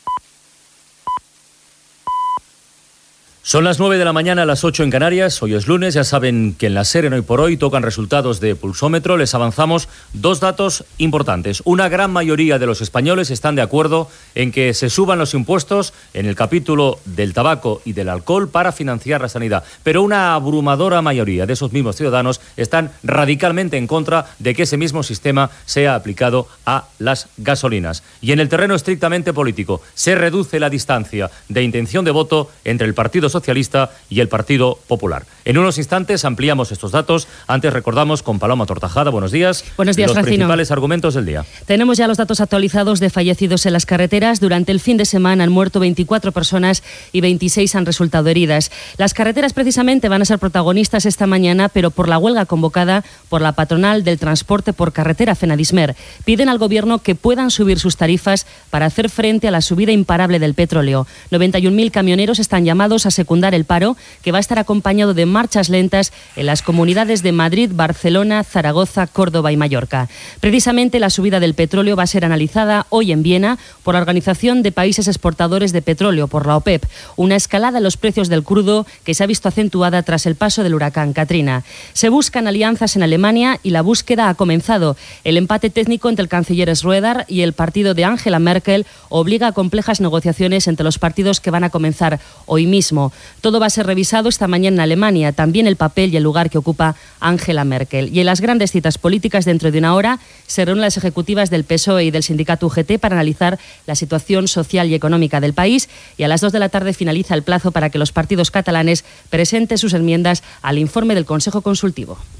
Primera emissió del programa amb Carles Francino.
Info-entreteniment